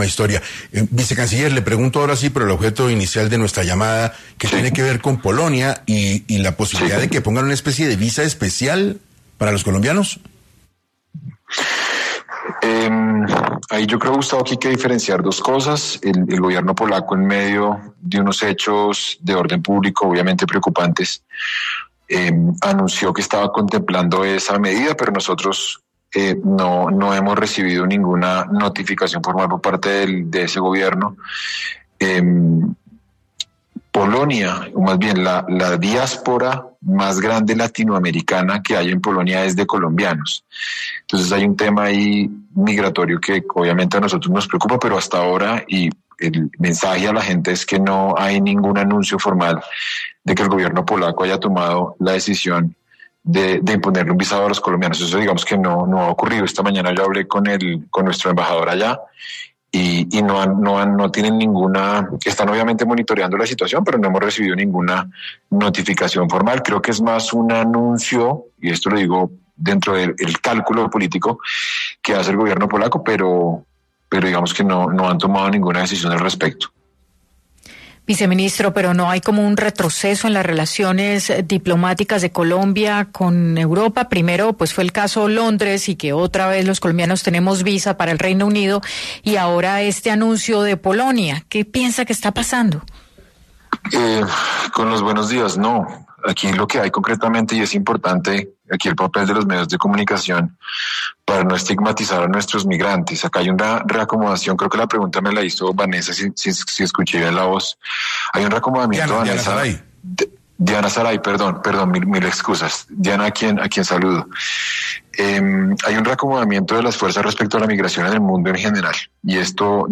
En entrevista con 6AM de Caracol Radio, Jaramillo explicó que el gobierno polaco en medio de “unos hechos de orden público obviamente preocupantes” anunció que estaba contemplando la medida.